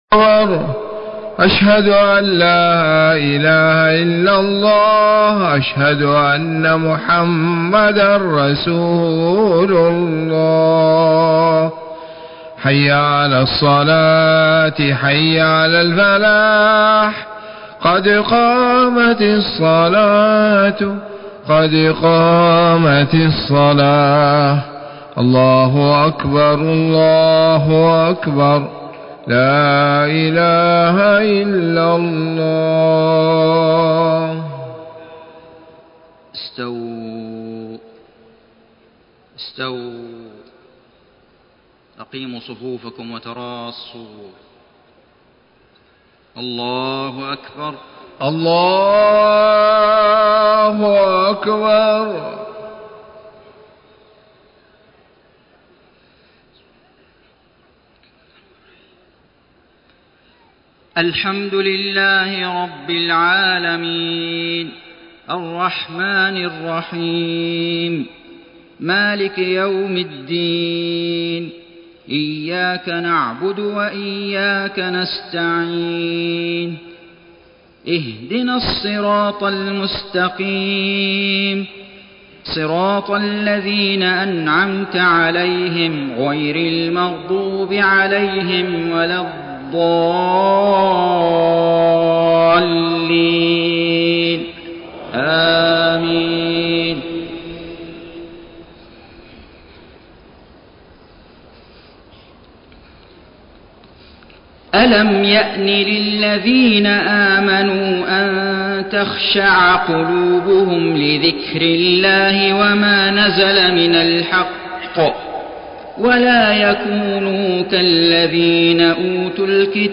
صلاة العشاء 3 ذو القعدة 1432هـ من سورة الحديد 16-24 > 1432 🕋 > الفروض - تلاوات الحرمين